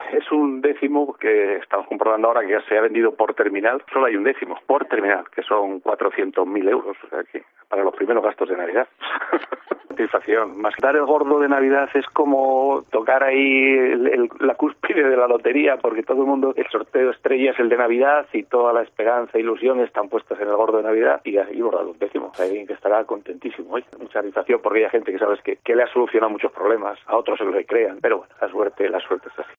Responde a la llamada de COPE y bromea: "Creo que es suficiente cantidad para afrontar los primeros gastos de Navidad"